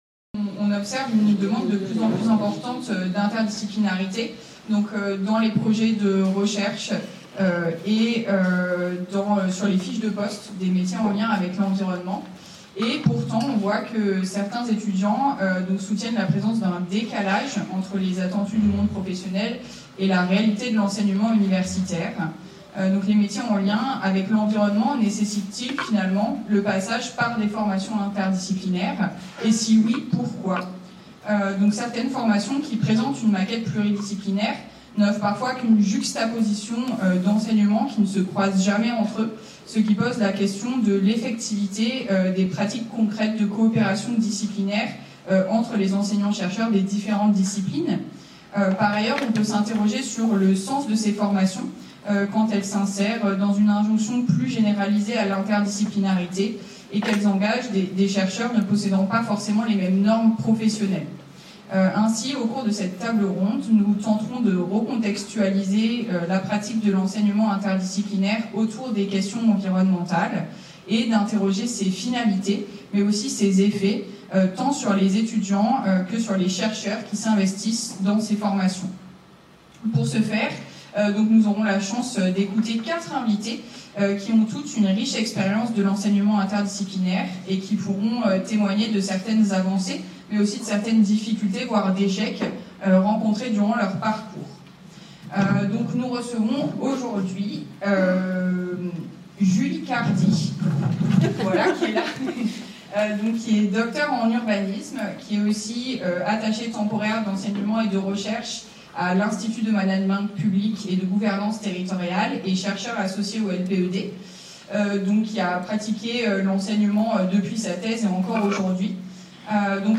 Table-ronde - Apprendre et enseigner l’interdisciplinarité : bilan et perspectives